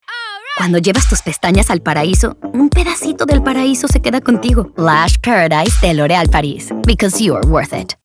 Commercial
Confident - Authoritative